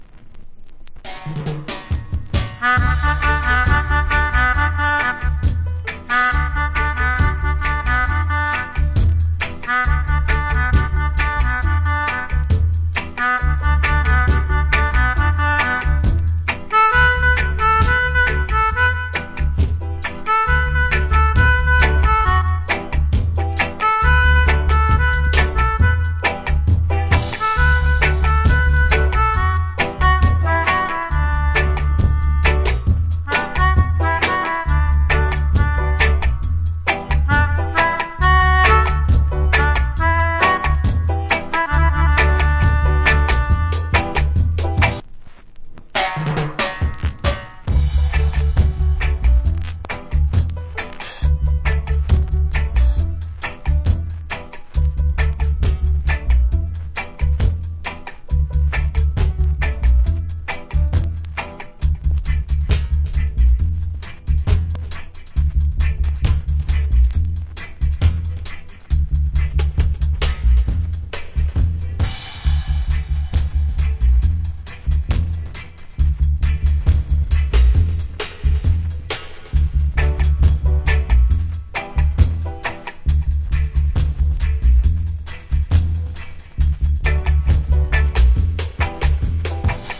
Great Melodica Inst.